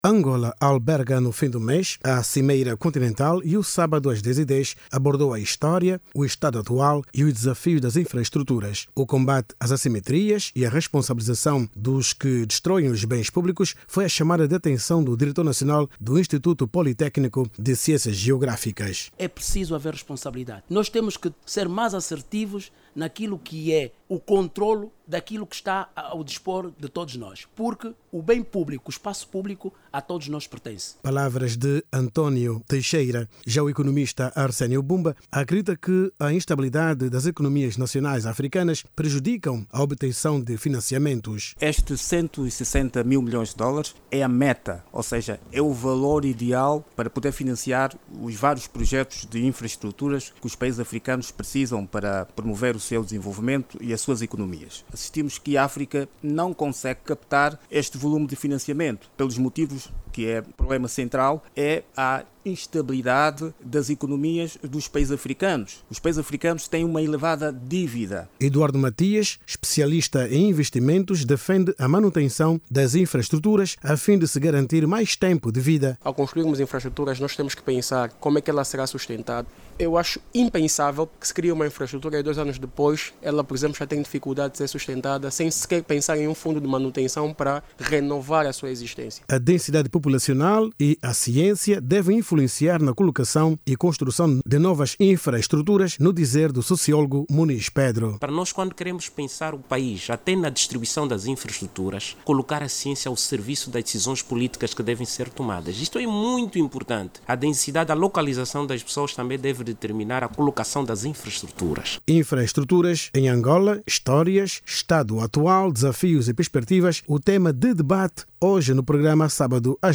O progresso das infraestruturas no país, vai ser destacado durante a Cimeira sobre financiamento para o desenvolvimento de infraestruturas em África a ter lugar de 28 a 31 deste mês na capital angolana. Angola, espera com a realização do evento, mobilizar para o continente até cento e sessenta mil milhões de dólares. O assunto, esteve em análise no programa sábado às 10 e 10 da Rádio Nacional de Angola.